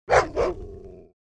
جلوه های صوتی
دانلود صدای گرگ 10 از ساعد نیوز با لینک مستقیم و کیفیت بالا